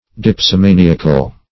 Dipsomaniacal \Dip`so*ma*ni"a*cal\, a. Of or pertaining to dipsomania.
dipsomaniacal.mp3